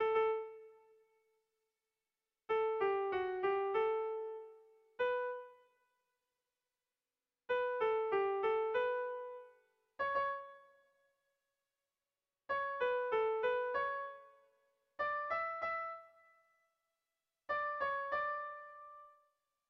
Irrizkoa
Lauko txikia (hg) / Bi puntuko txikia (ip)
AB